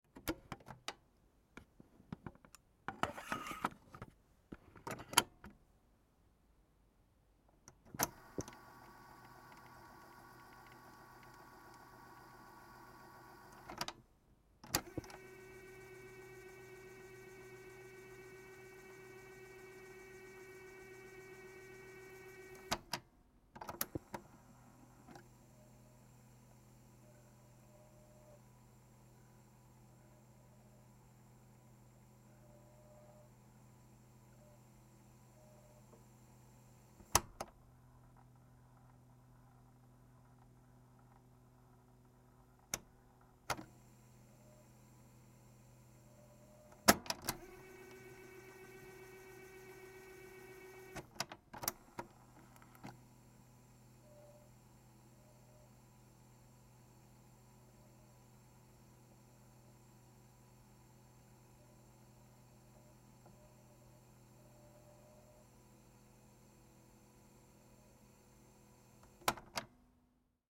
Sony CFD-101 radio cassette recorder